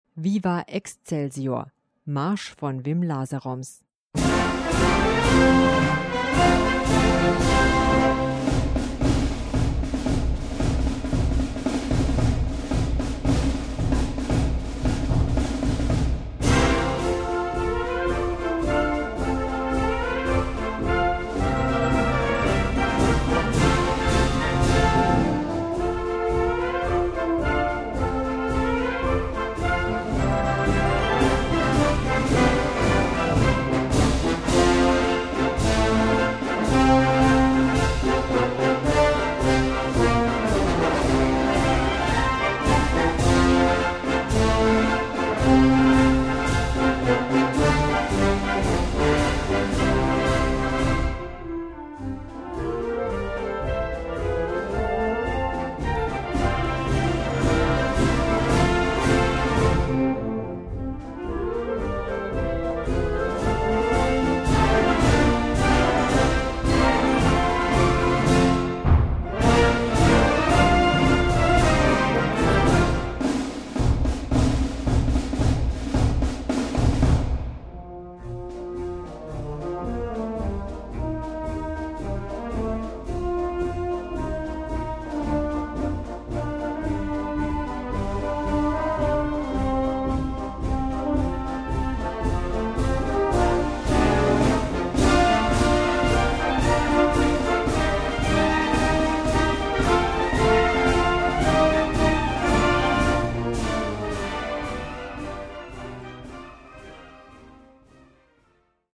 Gattung: Straßenmarsch
Besetzung: Blasorchester